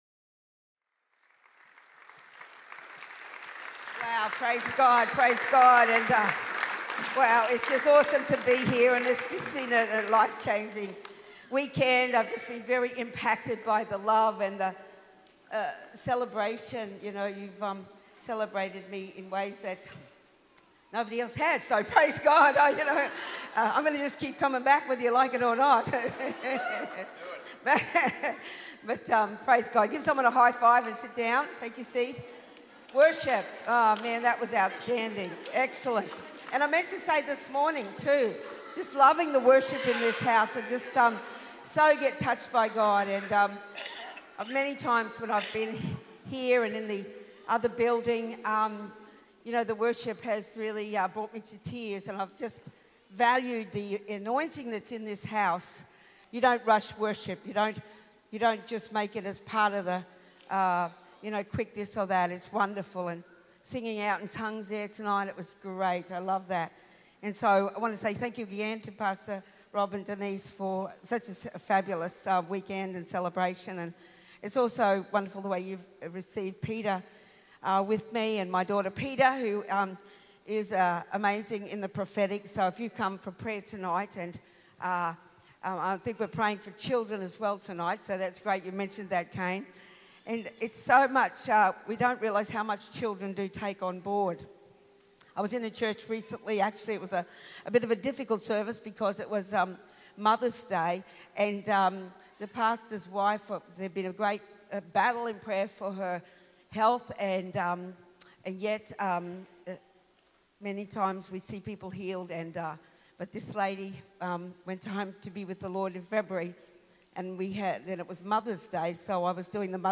PM Service